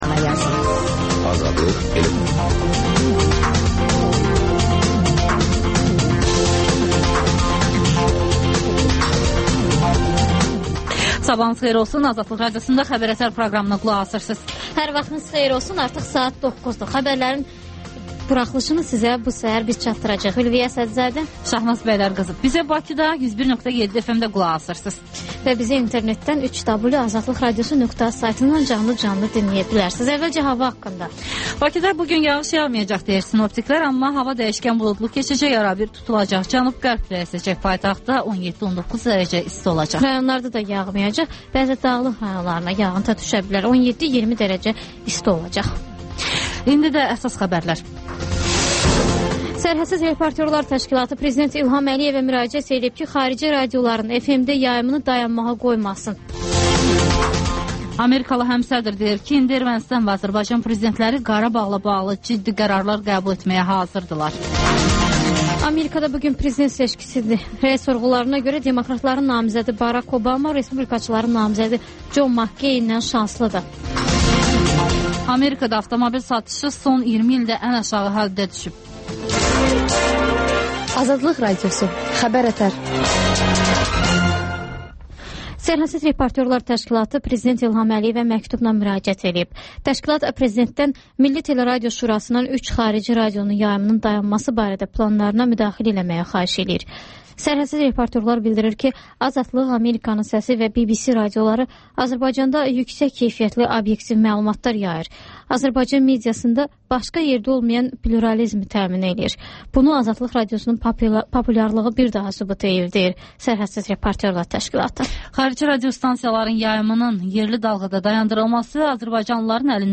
Xəbər-ətər: xəbərlər, müsahibələr, sonra İZ mədəniyyət proqramı